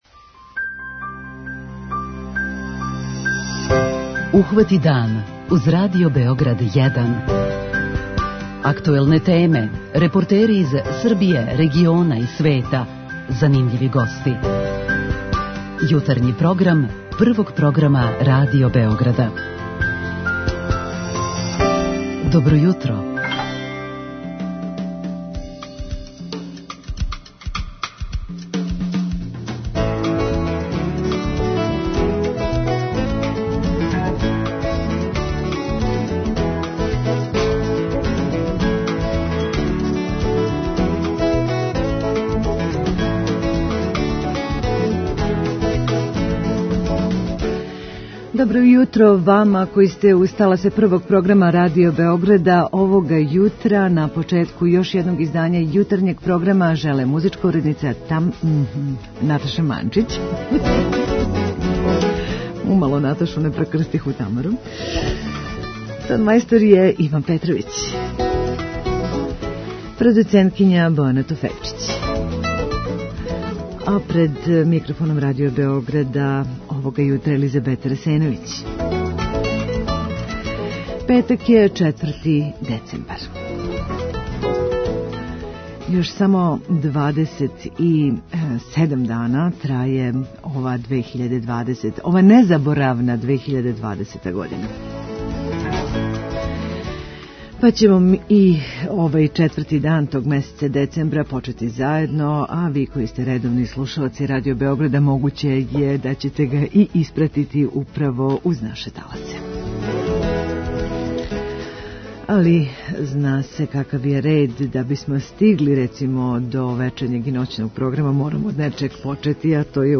Здравствени систем Србије од овог јутра има нову установу - наменски и у најкраћем року погигнуту, ковид болницу у Батајници капацитета готово хиљаду болничких лежајева. Са свечаности отварања, у Јутарњем програму, извештај репортера са првим утисцима и најважнијим информацијама. Осврнућемо се и на нове мере Владе Србије у борби са вирусом корона које данас ступају на снагу, а које доносе значајне рестрикције у раду многих услужних и рекреативних објеката.